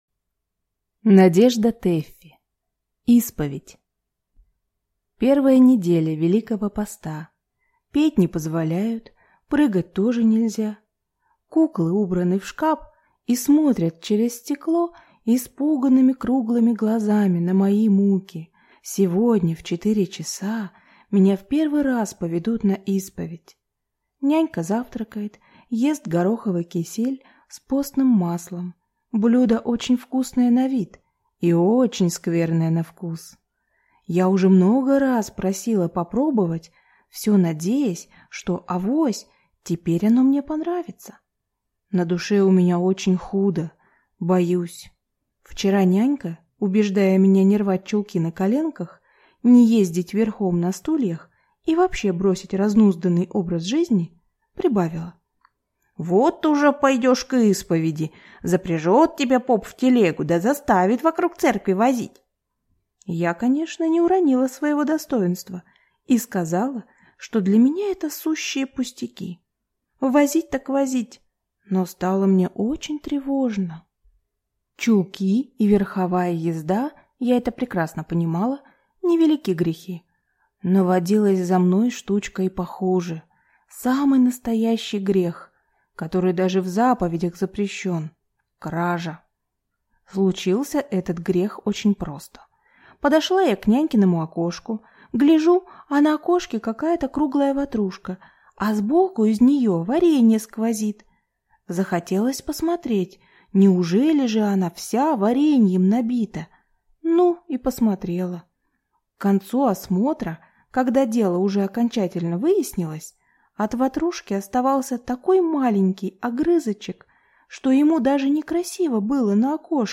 Аудиокнига Исповедь | Библиотека аудиокниг